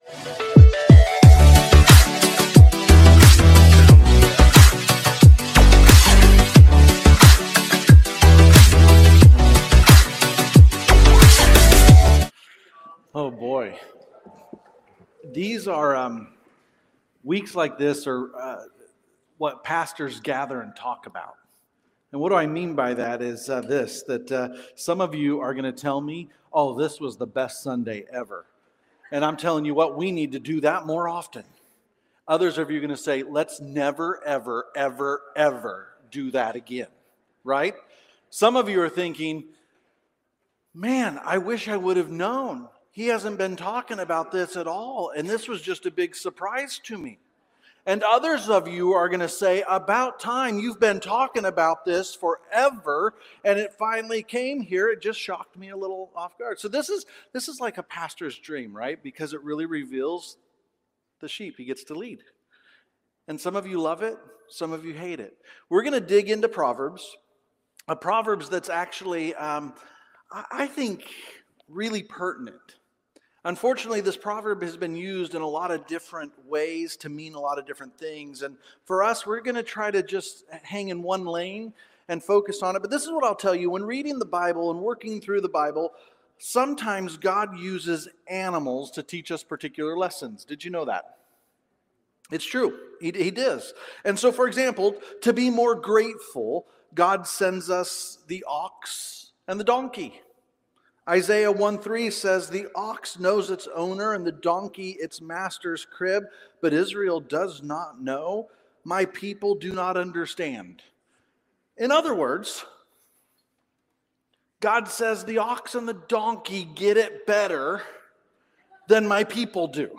Sermons | Mountainview Christian Church